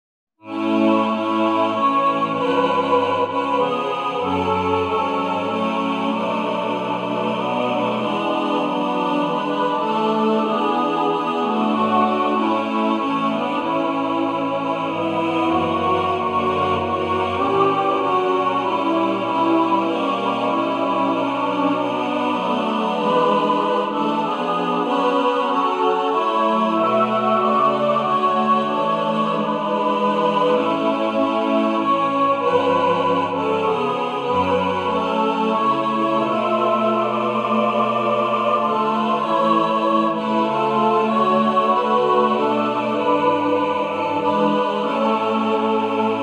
A dramatic, sacred hymn